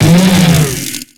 Cri de Lampéroie dans Pokémon X et Y.